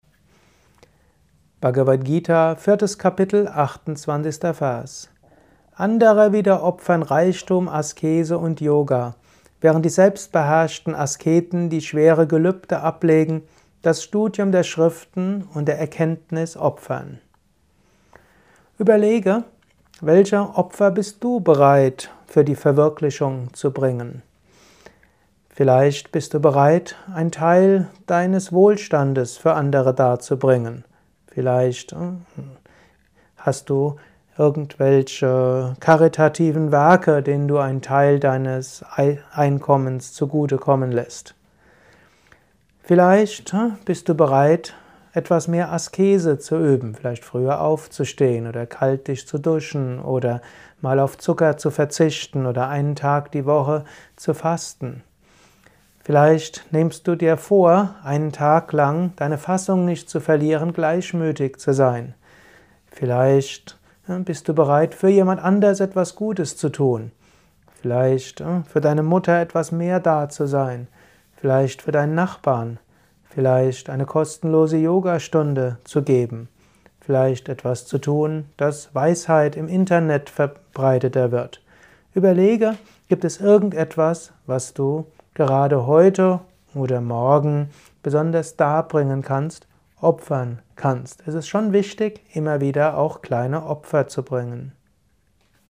Für welches Opfer bist du bereit. Dies ist ein kurzer Kommentar